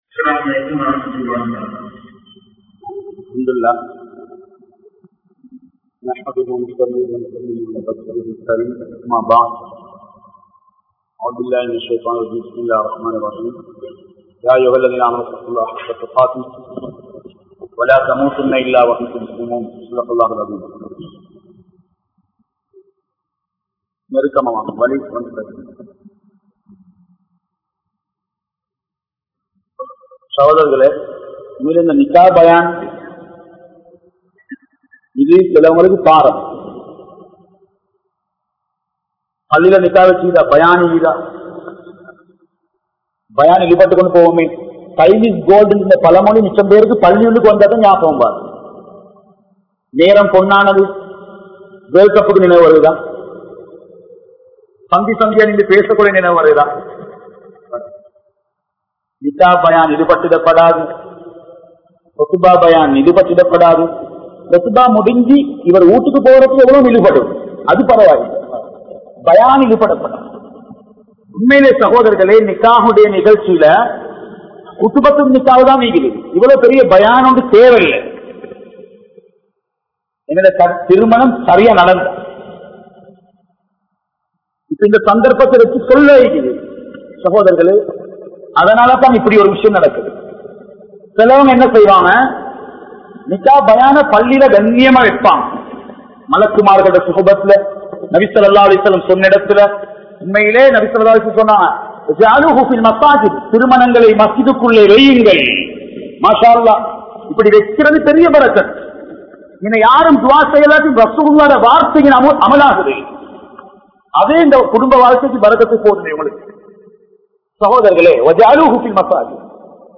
Seethanaththaal Alium Samooham (சீதனத்தால் அழியும் சமூகம்) | Audio Bayans | All Ceylon Muslim Youth Community | Addalaichenai
Colombo 12, Aluthkade, Muhiyadeen Jumua Masjidh